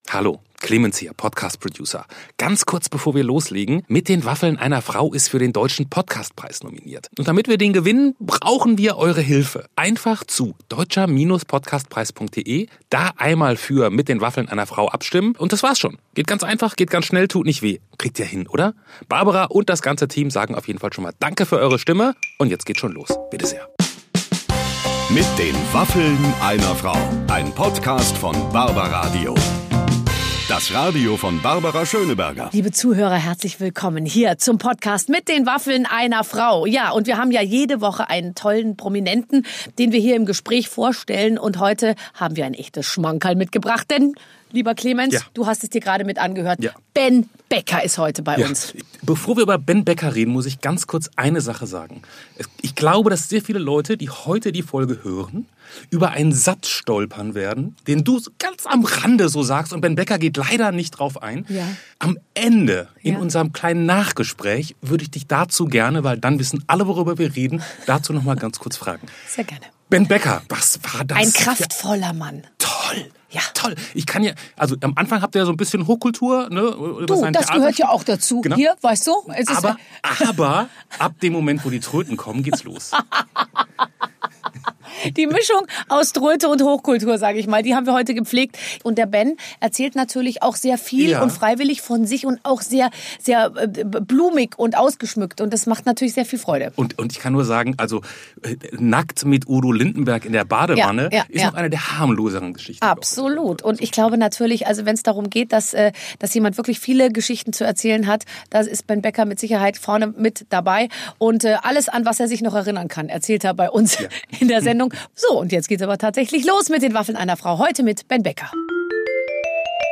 Im Interview mit Barbara Schöneberger spricht Ben Becker ganz offen über seine Affäre mit Liz Hurley. Und: Barbara Schöneberger hat schon mal einen Heiratsantrag angenommen und diesen bereits am nächsten Tag wieder annulliert.